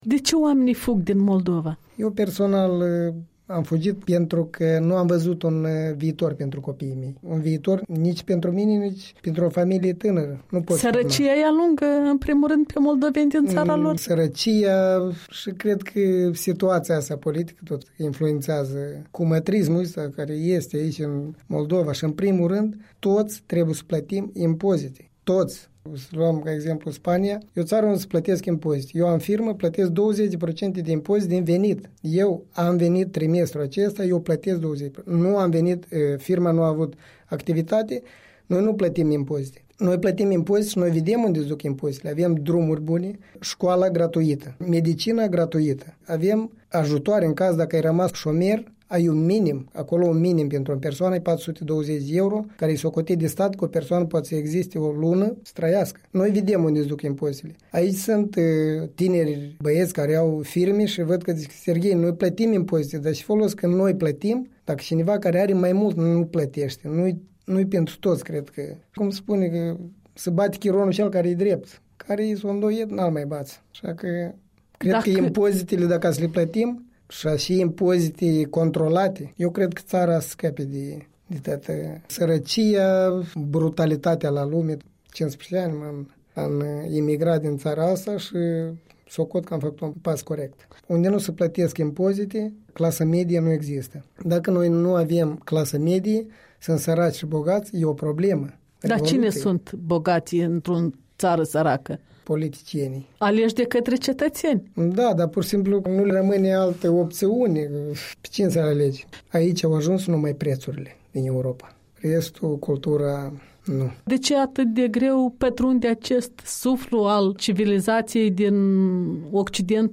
Interviu cu un moldovean stabilit în Spania de 15 ani